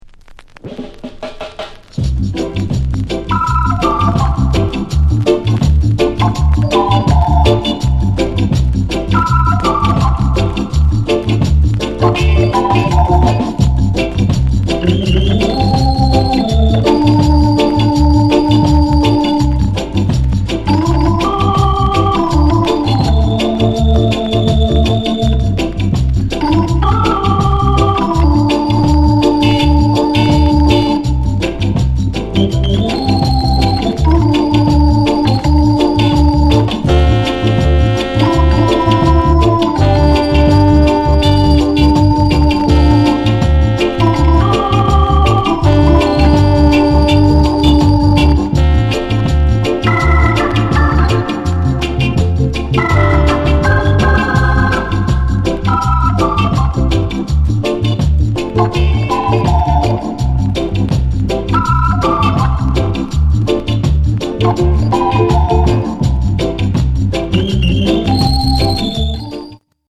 SOUND CONDITION A SIDE VG(OK)
NICE INST